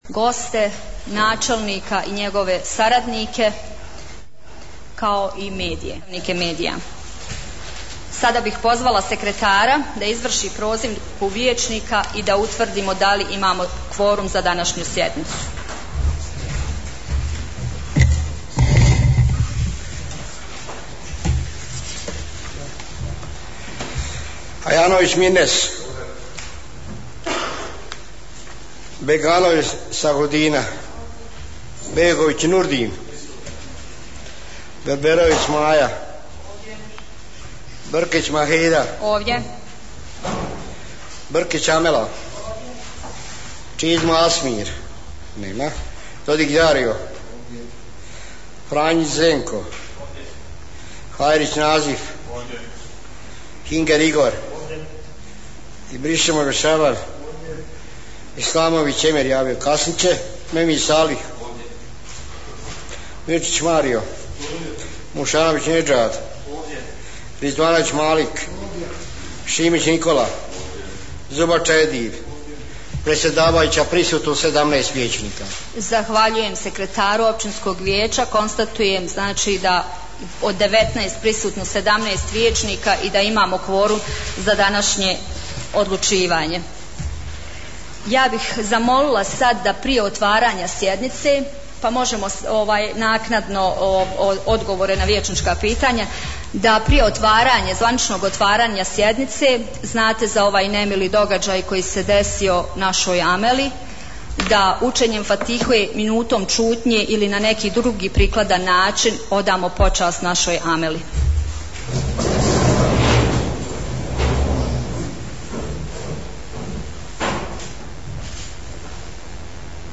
29.12.2021. godine održana je 11. sjednica Općinskog vijeća Vareš.